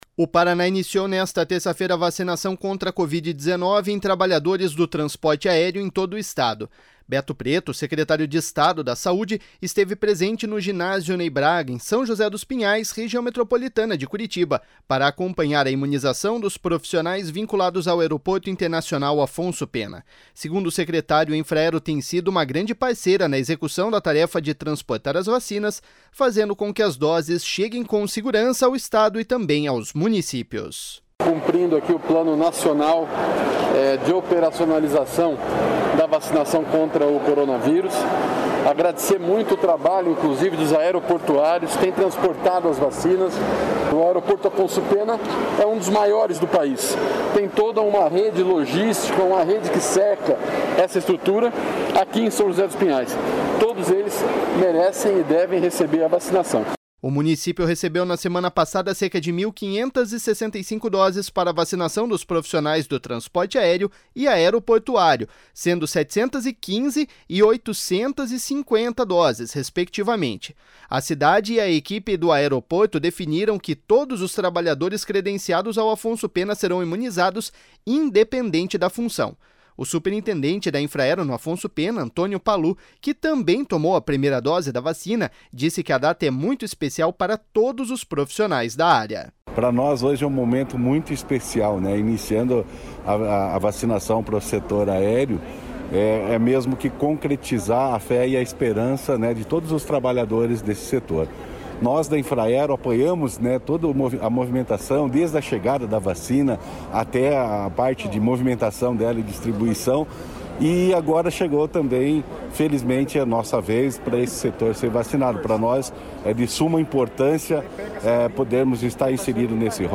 Segundo o secretário, a Infraero tem sido uma grande parceira na execução da tarefa de transportar as vacinas, fazendo com que as doses cheguem com segurança ao Estado e também aos municípios.// SONORA BETO PRETO.//
A prefeita Nina Singer agradeceu o empenho do Governo do Estado para que as vacinas cheguem aos municípios e atinjam um maior número de pessoas.// SONORA NINA SINGER.//